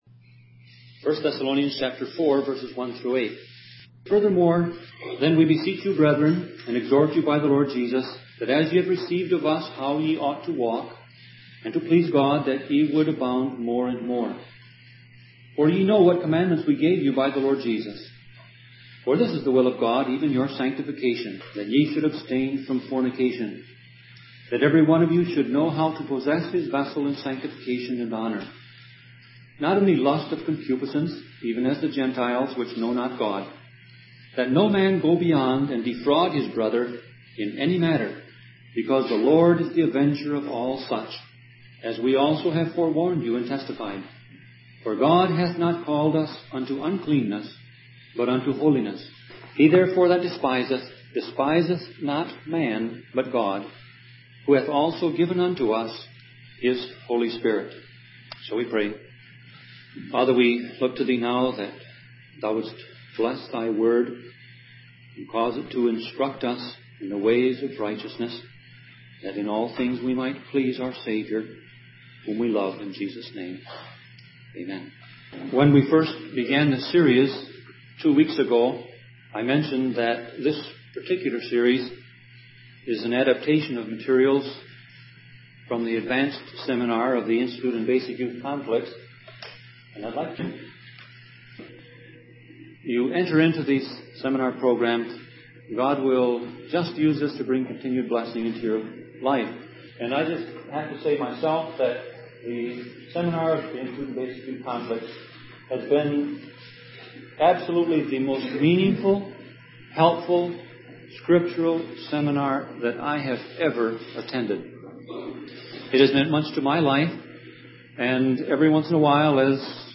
Sermon Audio Passage: 1 Thessalonians 4:1-8 Service Type